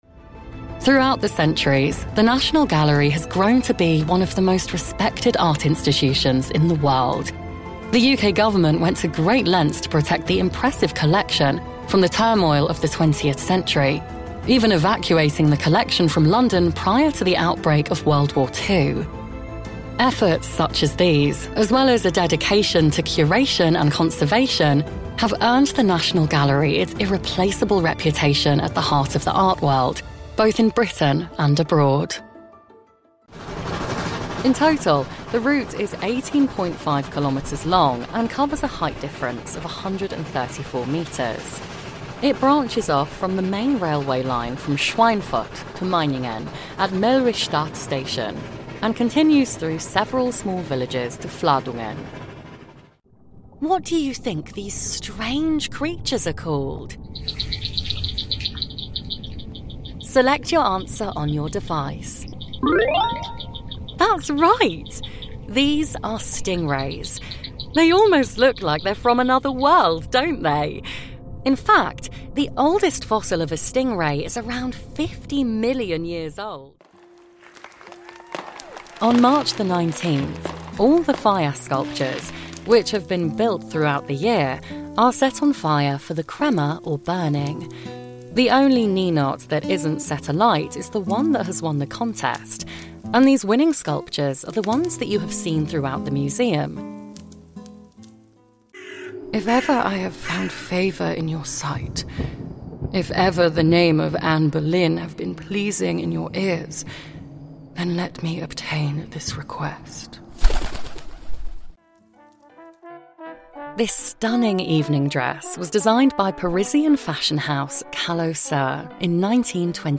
Audioguides
Mein natürlicher London-Estuary-Akzent, kombiniert mit einem sympathischen Millennial-Charme, ermöglicht es mir, mich nahtlos an verschiedene Projekte anzupassen.
Meine klare, gesprächige Stimme macht komplexe Themen zugänglicher und ansprechender und verwandelt selbst die banalsten Inhalte in etwas Spannendes und Unterhaltsames.
SessionBooth doppelwandige Gesangskabine mit Akustikpaneelen
Lewitt LCT 540 S Mikrofon